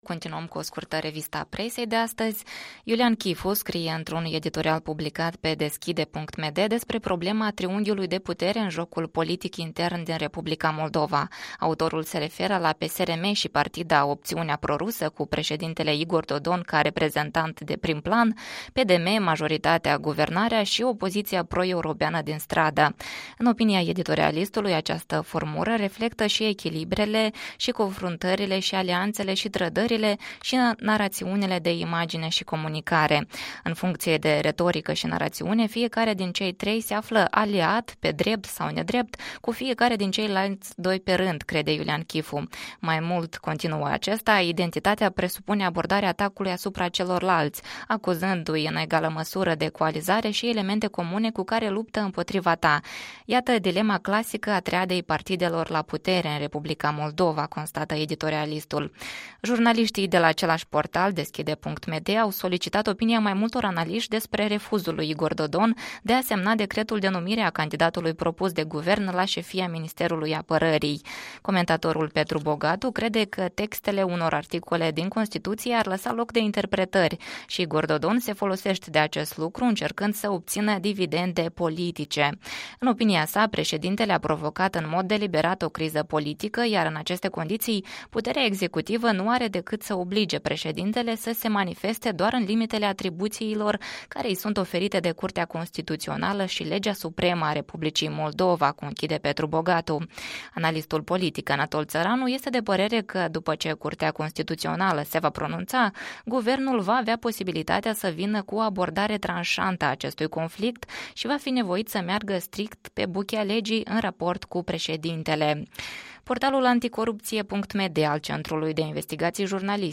Revista presei matinale de la Chișinău